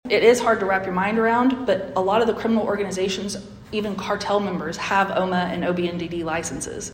CLICK HERE to listen to comments from Adria Berry on marijuana-related crime.